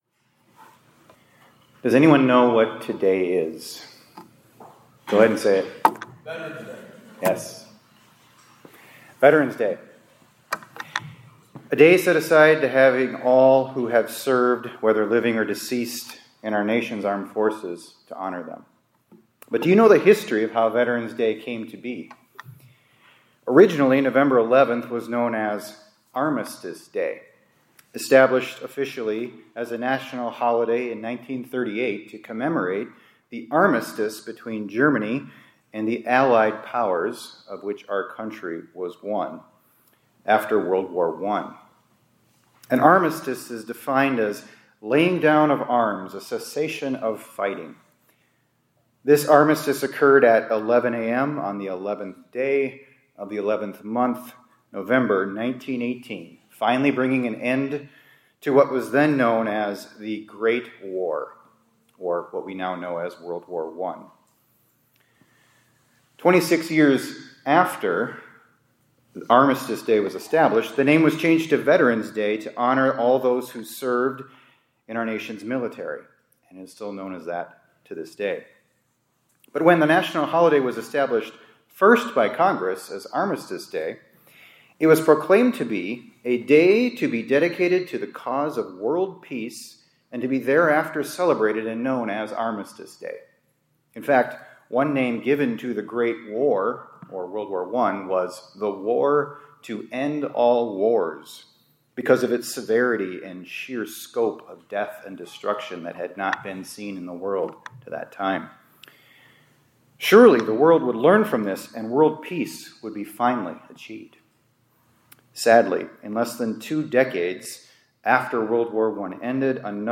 2025-11-11 ILC Chapel — Jesus Brings True Peace